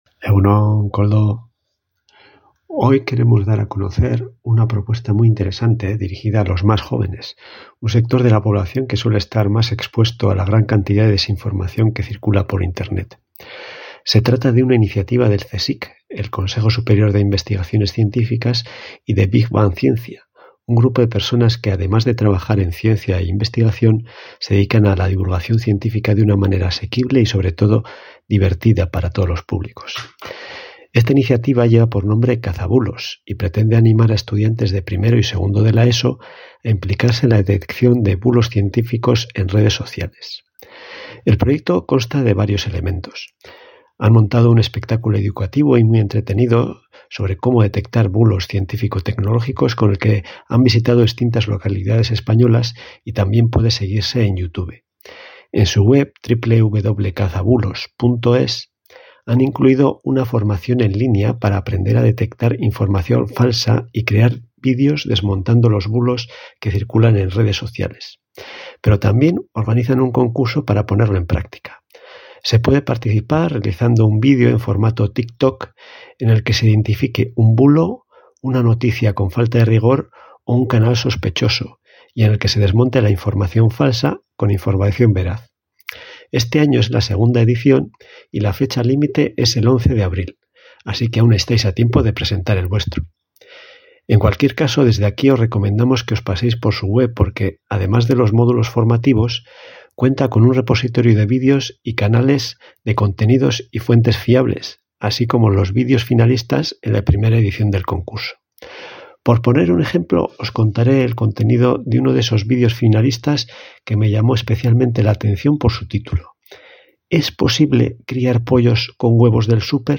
Logos Elkartea nos habla del proyecto Cazabulos